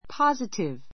positive 中 pɑ́zətiv パ ズィティ ヴ 形容詞 ❶ 積極的な ; 肯定 こうてい 的な; 前向きな a positive answer a positive answer （はいという）肯定の答え ❷ 明確な, はっきりした; 確信のある positive proof positive proof 確実な証拠 しょうこ , 確証 I'm positive about [of] it.